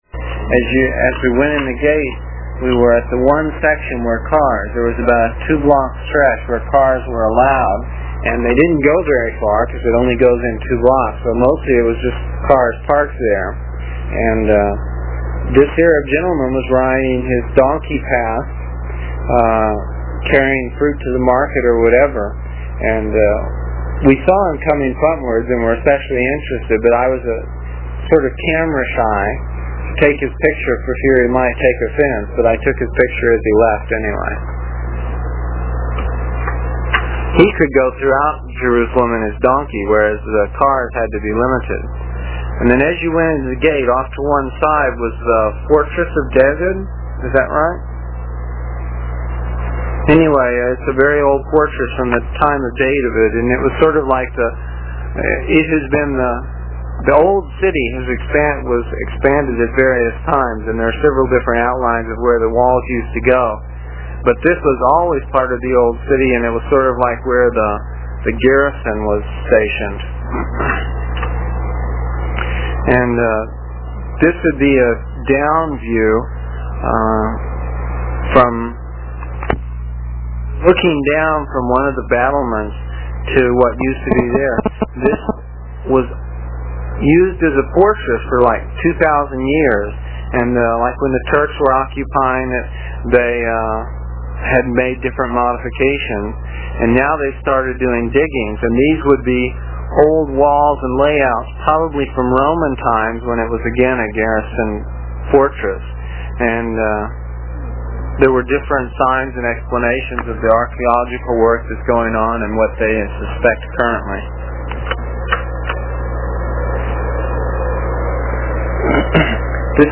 It is from the cassette tapes we made almost thirty years ago. I was pretty long winded (no rehearsals or editting and tapes were cheap) and the section for this page is about nine minutes and will take about three minutes to download with a dial up connection.